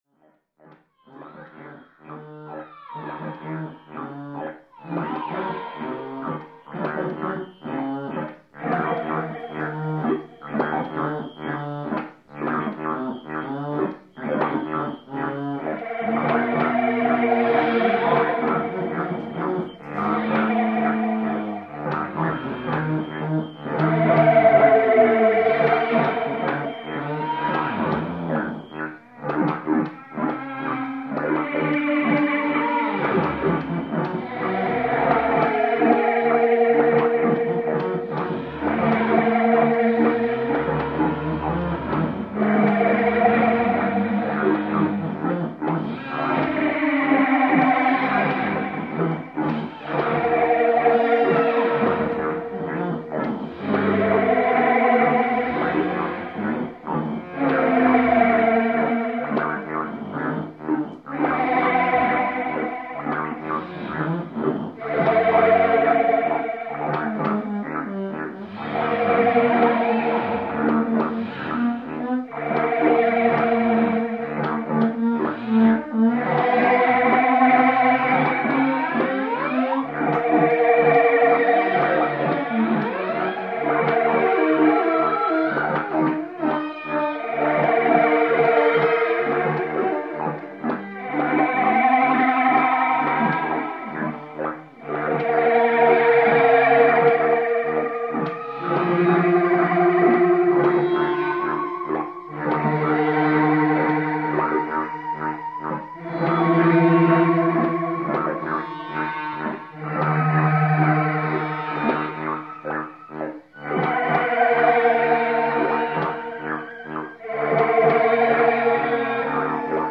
three-theremin